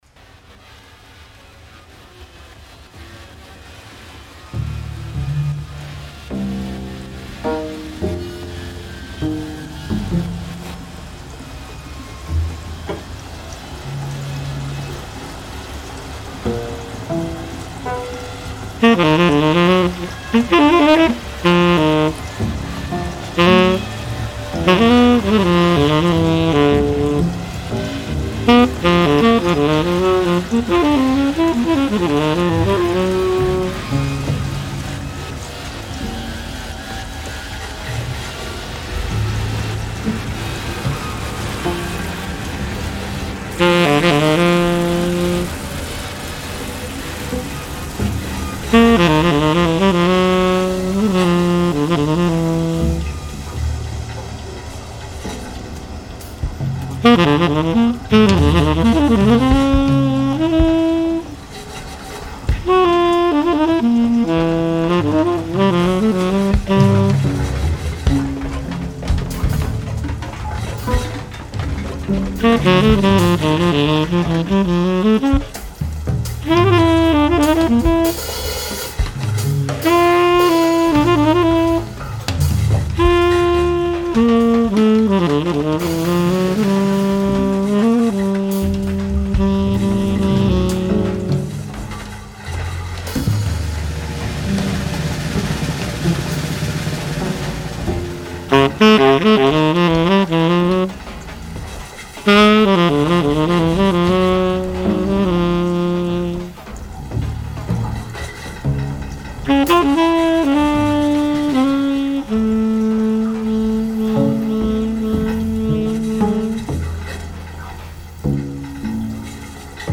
live streamed
Live Music, Saxophone
(Live mashed) sonic fictions